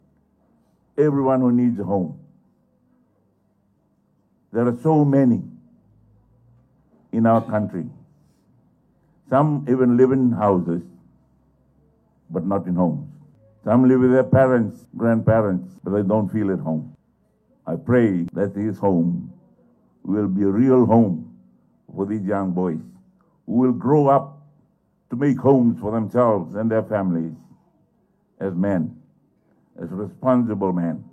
These were the sentiments of Rabuka during the inauguration ceremony which was attended by distinguished guests, members of the community and wards of the home.
Prime Minister Sitiveni Rabuka.